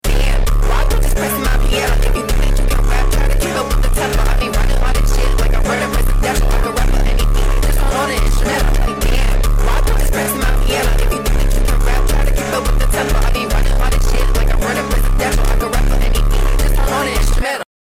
(also sorry for the quality) Also the art is by me!